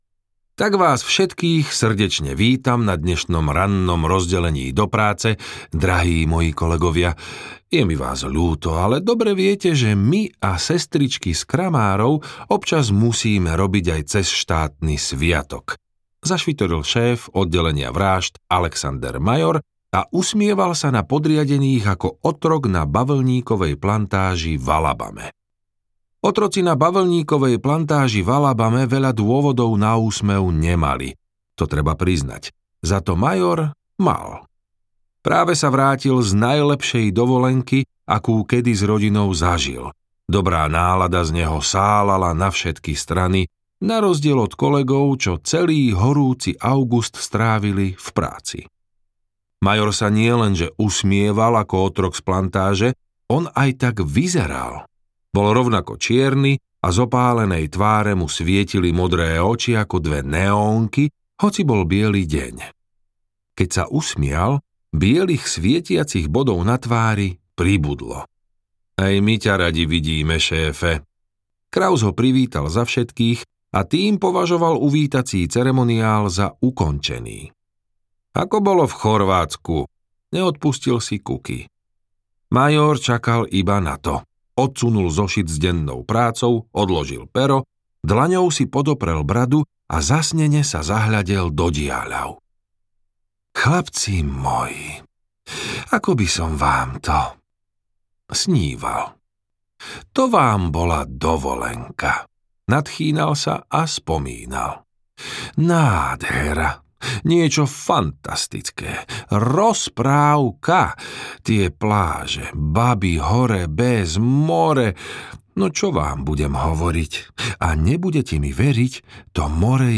Kráska a netvor audiokniha
Ukázka z knihy